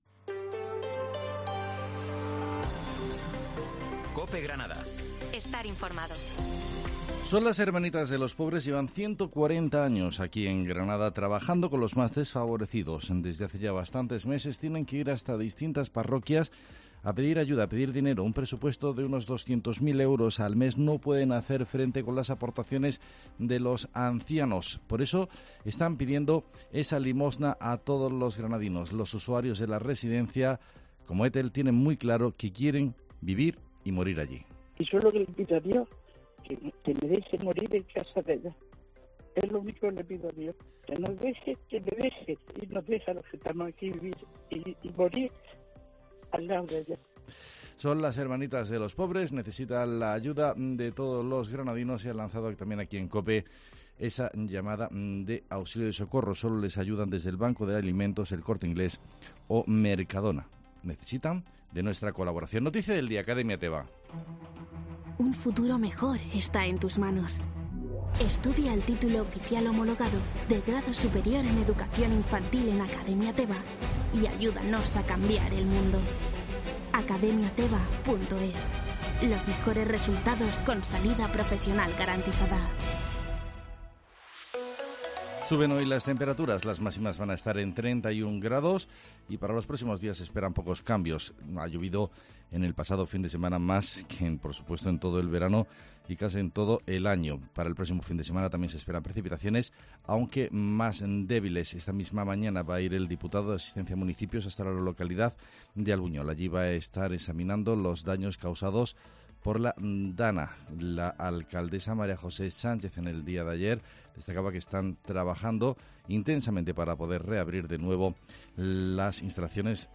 Herrera en COPE Granada, Informativo del 5 de septiembre